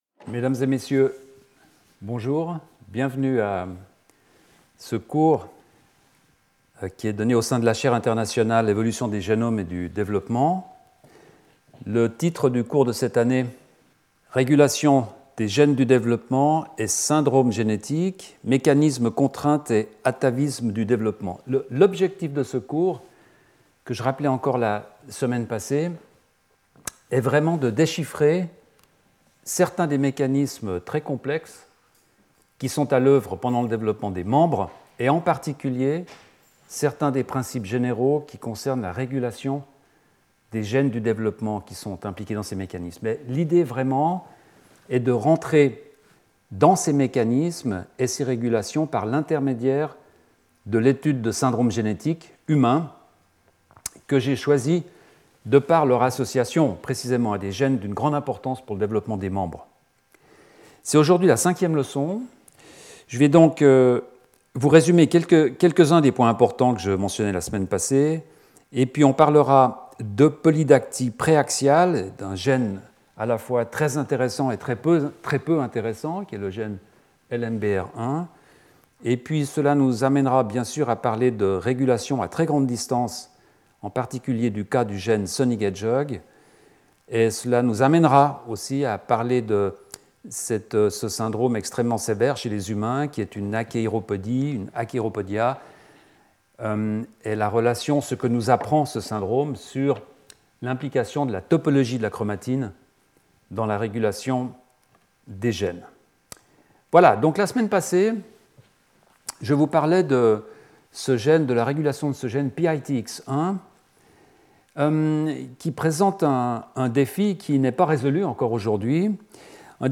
Skip youtube video player Listen to audio Download audio Audio recording In this fifth lecture, the long-range regulation of the Shh gene is discussed, first by describing the work leading to the characterization of ZRS, the main sequence involved in the expression of this gene in the limbs. Next, different types of mutations affecting this ZRS sequence are described, all of which lead to a pre-axial polydactyly phenotype in which the anterior digits (the thumb) are usually duplicated or affected.